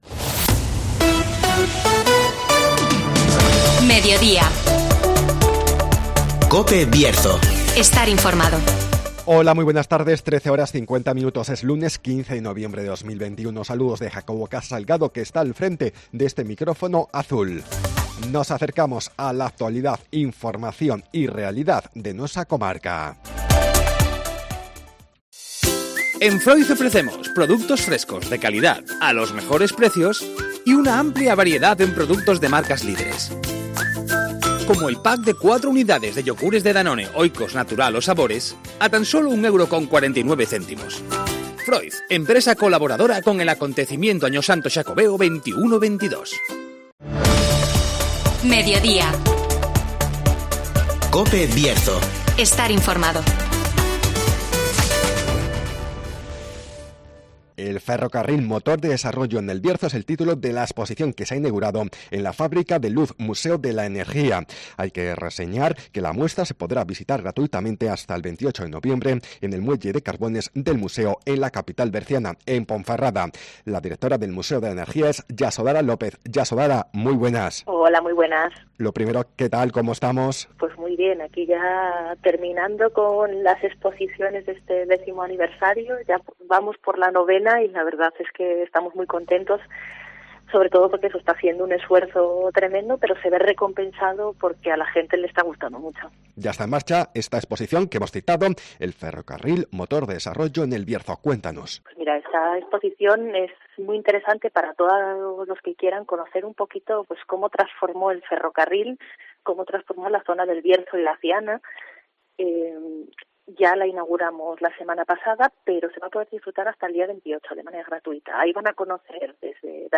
El ferrocarril protagoniza la nueva exposición en el Museo de la Energía (Entrevista